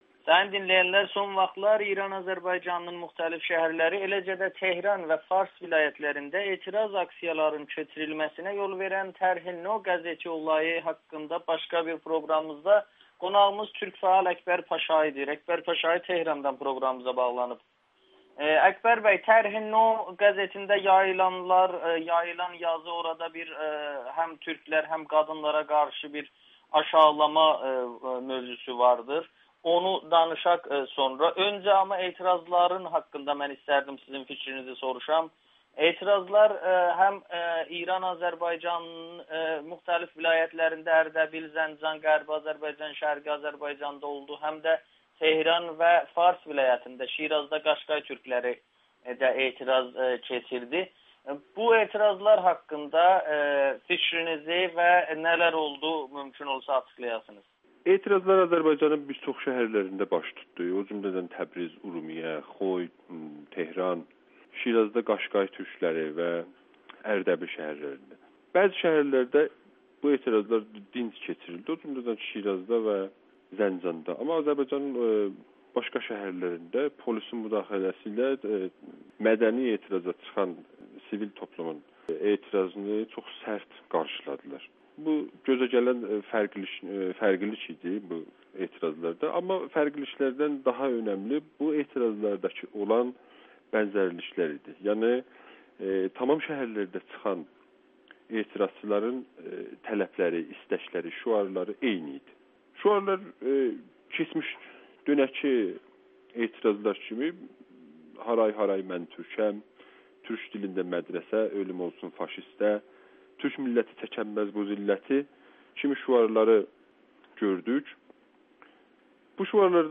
Etirazçılar türk kimliyinə sahib çıxdılar [Audio-Müsahibə]
Tehrandan siyasi fəal kütləvi etirazlar haqda danışır